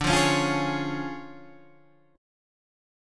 D Minor Major 7th Flat 5th